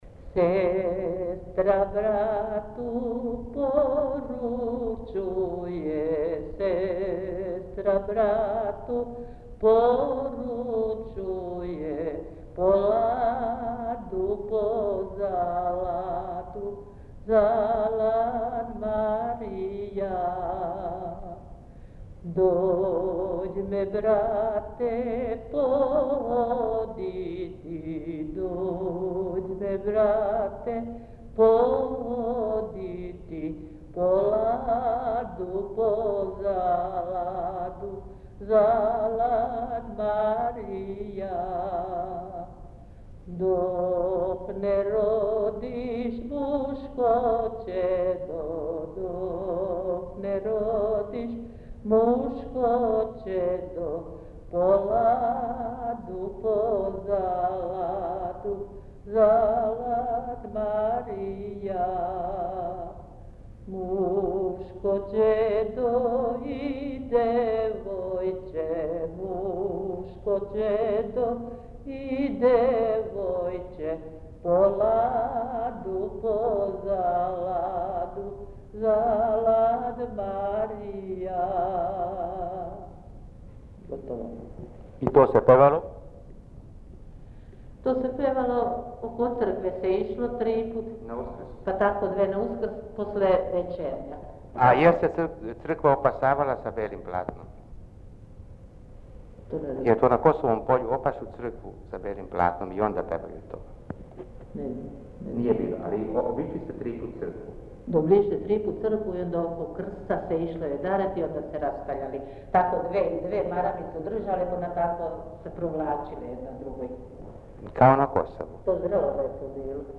Mesto: Kalaz
Napomena: Ove dve melodije izvedene su kao jedna celina. Reč je o srpskim obrednim pesmama, u prošlosti u Kalazu pevane na (pravoslavni) Uskrs posle večernje službe uz kolo sa provlačenjem, igrano oko crkve.